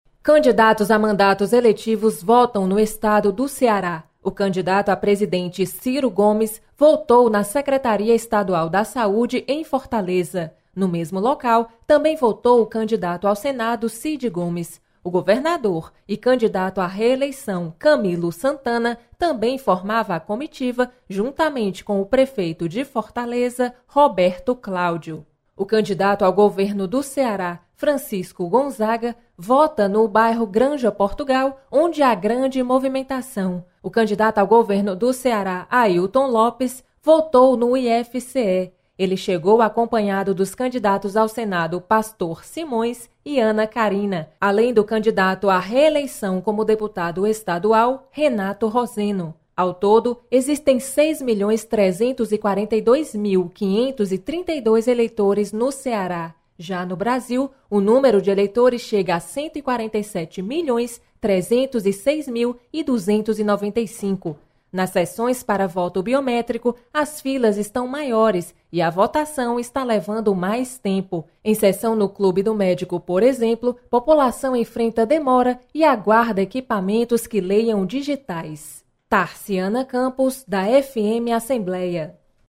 Você está aqui: Início Comunicação Rádio FM Assembleia Notícias Eleições 2018